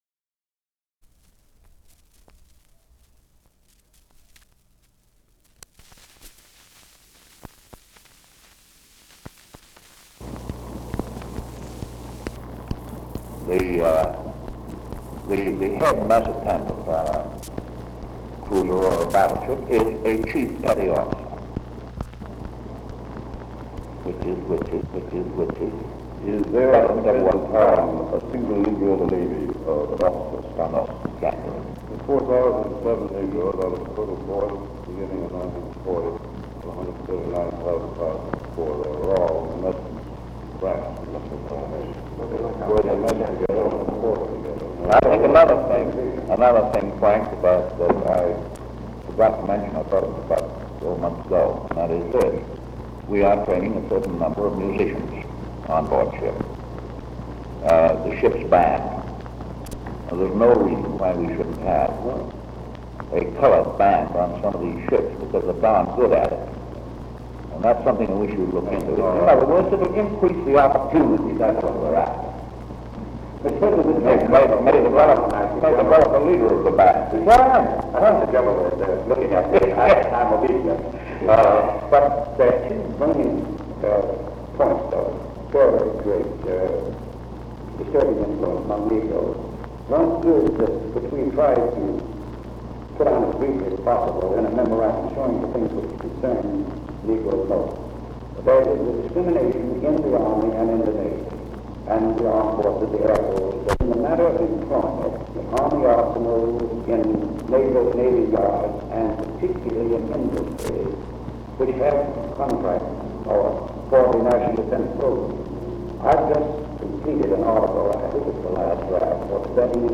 Office Conversation
Secret White House Tapes | Franklin D. Roosevelt Presidency